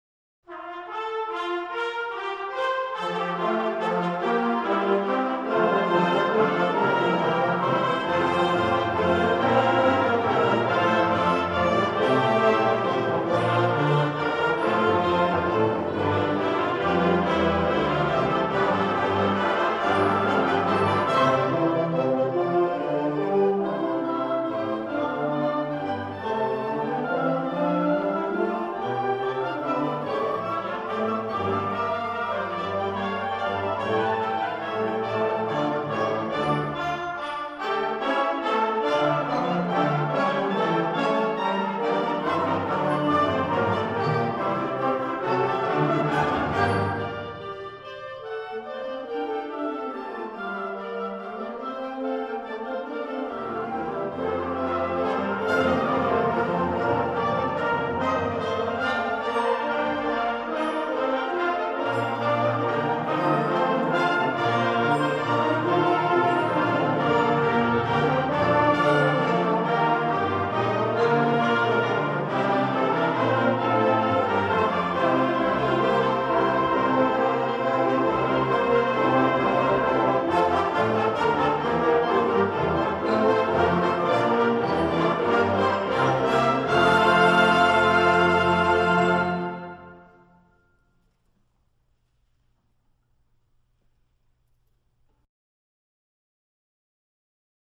Genre: Band
Instrumentation
Flute 1/2
English Horn
Bass Clarinet
Tuba
Timpani
Xylophone/Marimba